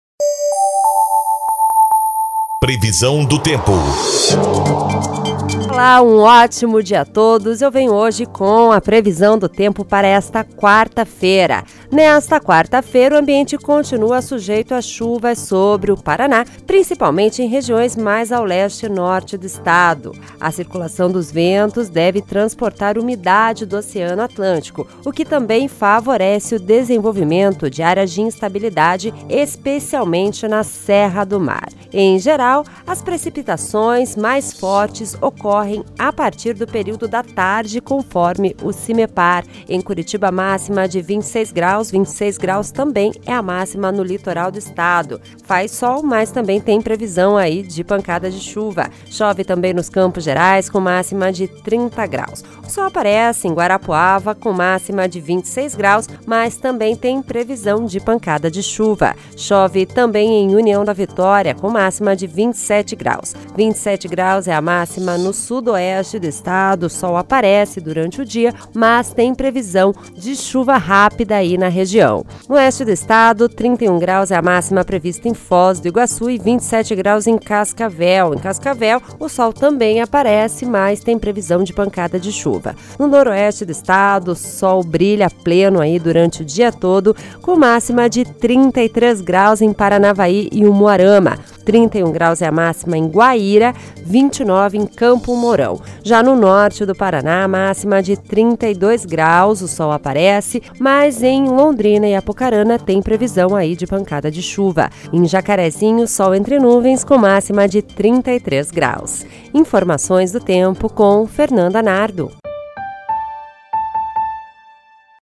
Previsão do Tempo (03/01)